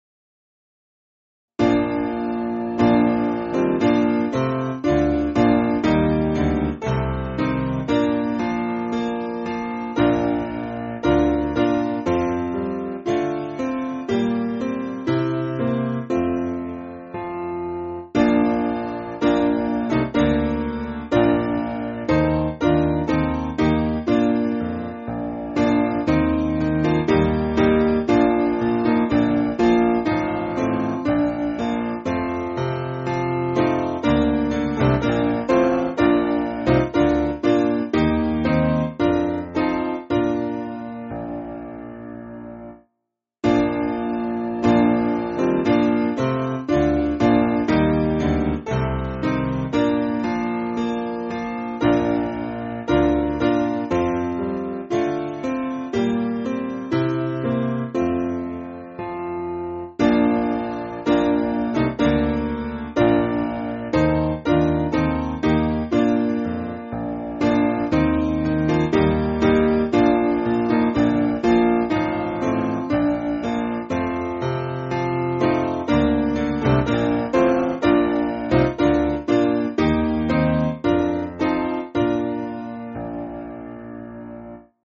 Simple Piano
(CM)   5/Bb